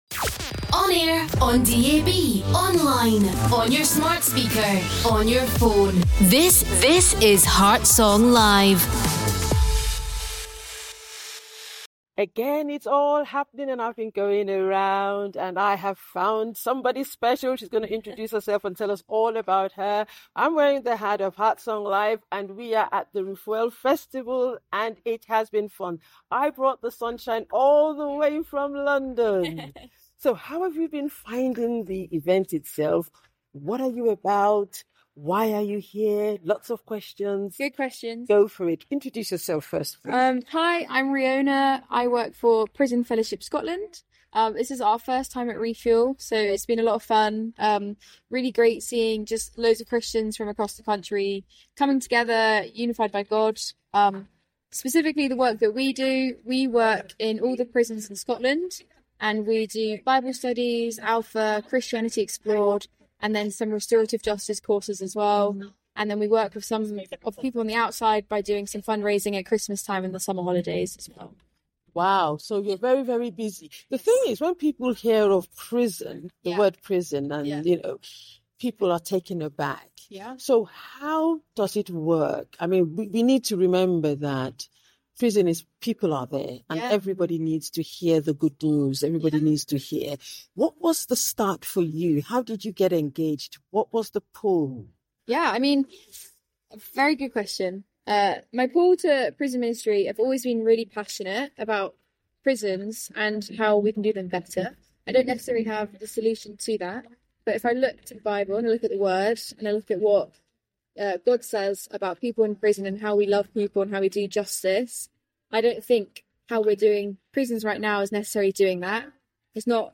In a compelling conversation with a dedicated staff member at Prison Fellowship Scotland, to shed light on the heart behind the mission.
Excerpts from interview